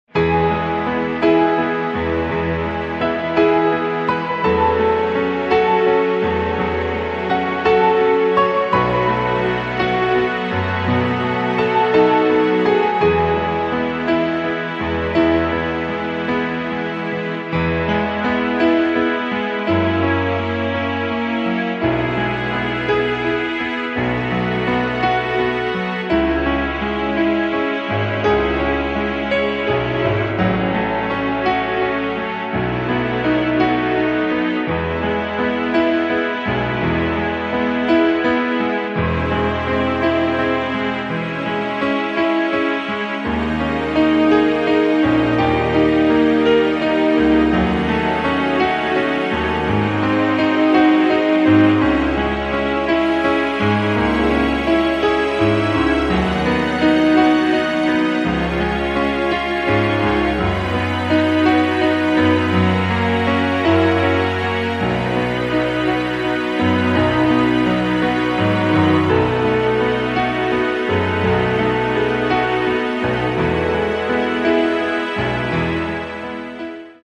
Pianoplayback – Instrumental
• Tonart: E Dur (weitere auf Anfrage)
• Art: Klavier Streicher Version
• Das Instrumental beinhaltet NICHT die Leadstimme
Klavier / Streicher